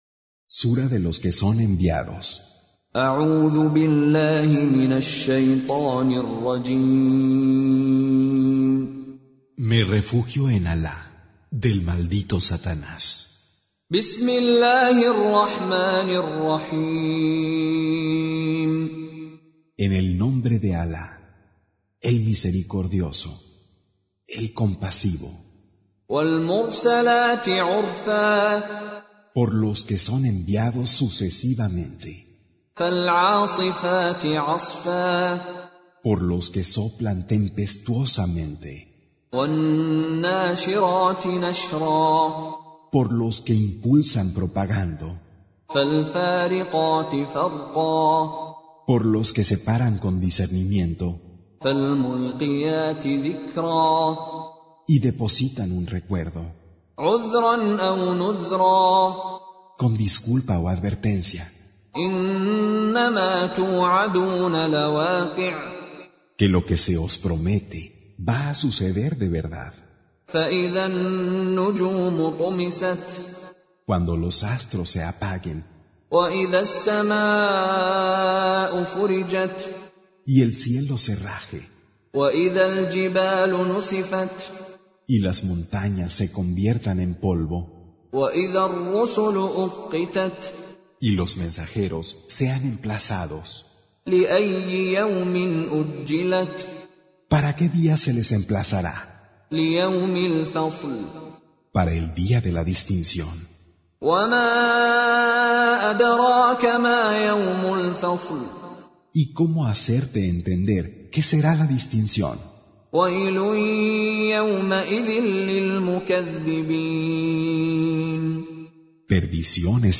Surah Sequence تتابع السورة Download Surah حمّل السورة Reciting Mutarjamah Translation Audio for 77. Surah Al-Mursal�t سورة المرسلات N.B *Surah Includes Al-Basmalah Reciters Sequents تتابع التلاوات Reciters Repeats تكرار التلاوات